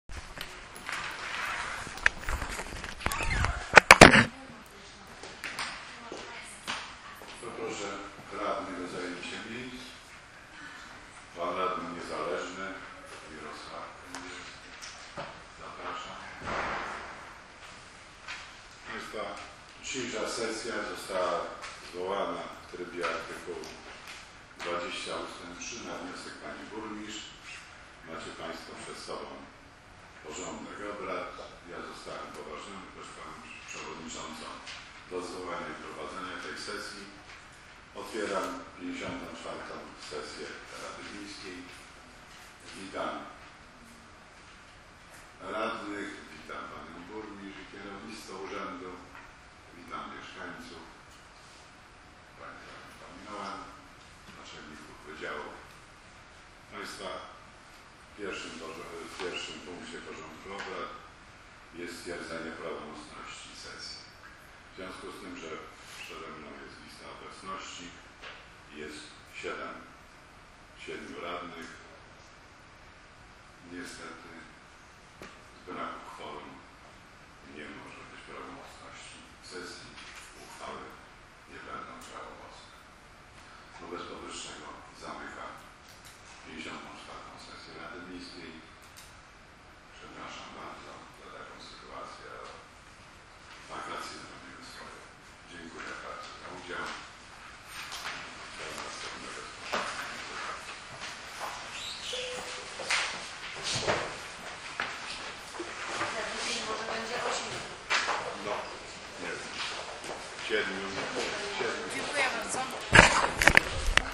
Plik dźwiekowy z Sesji Rady Miejskiej Nr LIV z dnia 1.08.2013    WMA 907.4 KB  2013-08-02